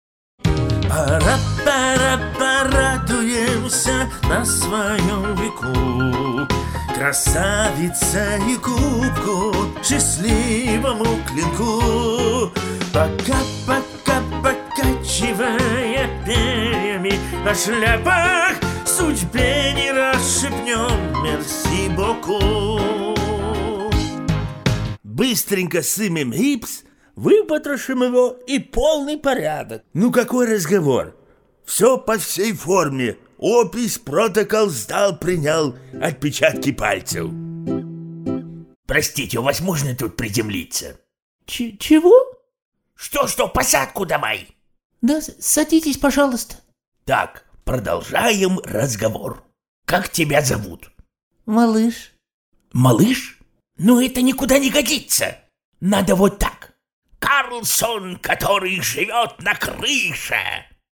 Профессиональная начитка на русском, итальянском и испанском языках.
Тракт: Микрофон AKG c214, аудиоинтерфейс RME Babyface Pro, DAW Samplitude